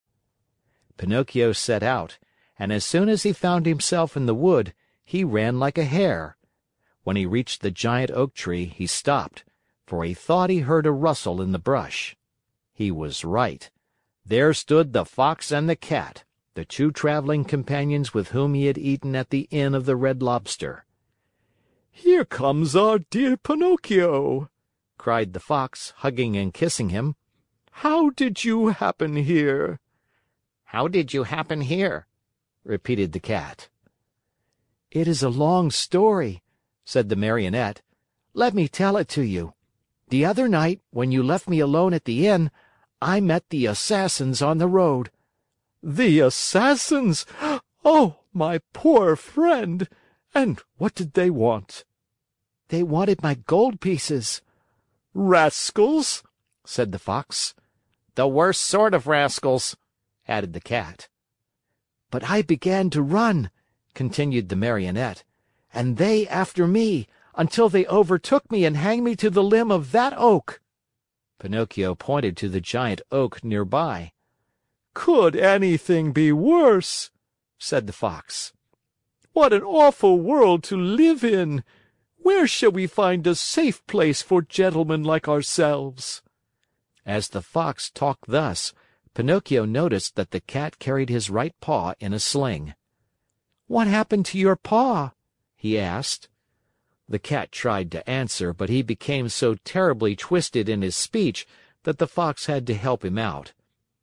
在线英语听力室木偶奇遇记 第51期:狐狸和猫(2)的听力文件下载,《木偶奇遇记》是双语童话故事的有声读物，包含中英字幕以及英语听力MP3,是听故事学英语的极好素材。